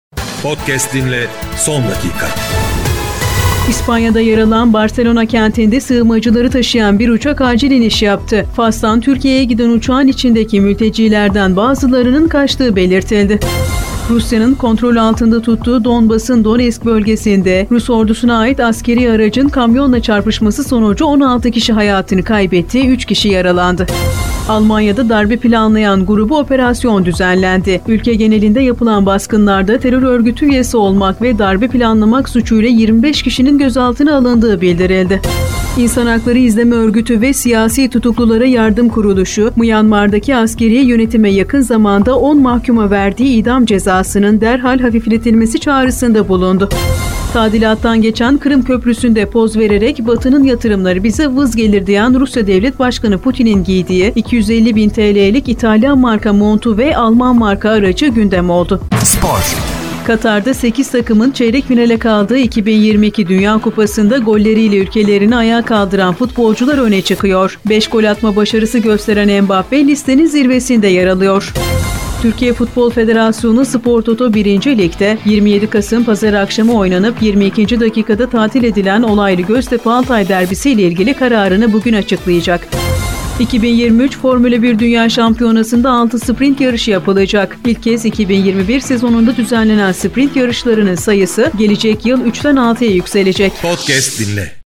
HABER-8-ARALIK-SABAH.mp3